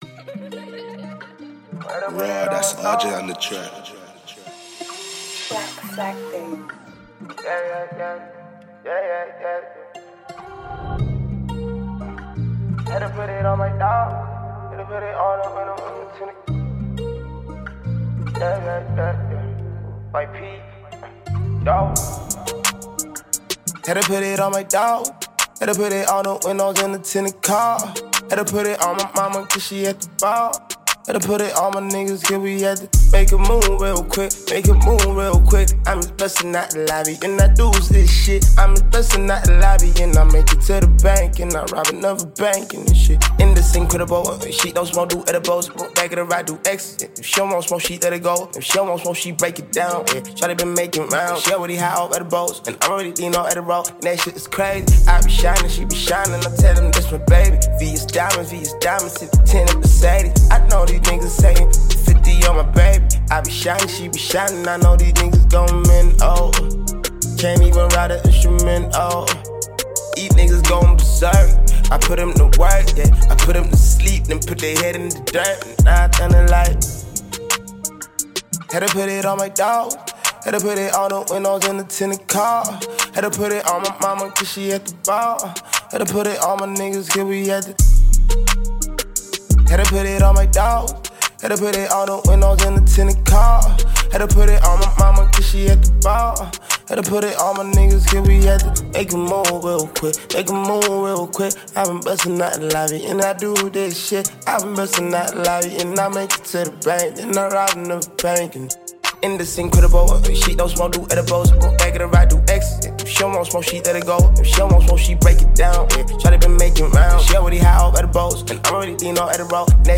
rapper and songwriter